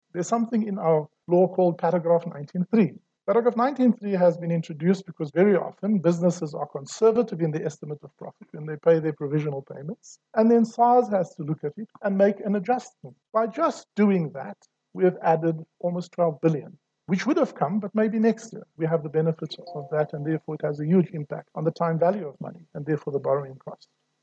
Kieswetter, speaking during an online event hosted by Deloitte, says SARS is constantly at work to improve its technical capabilities in order to deliver better services to taxpayers.